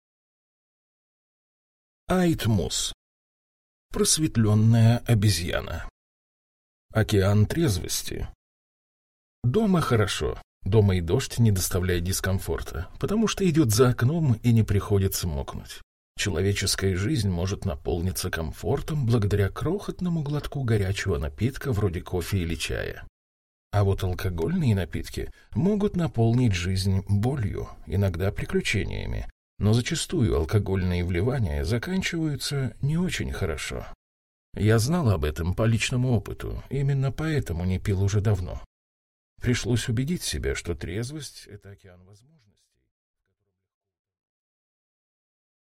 Аудиокнига Просветлённая обезьяна | Библиотека аудиокниг
Прослушать и бесплатно скачать фрагмент аудиокниги